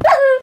1.21.4 / assets / minecraft / sounds / mob / wolf / hurt3.ogg
hurt3.ogg